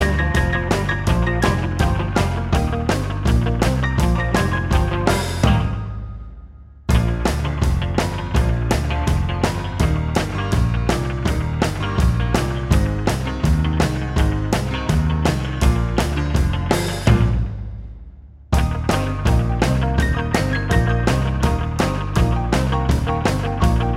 Minus Acoustic Guitar Rock 'n' Roll 2:34 Buy £1.50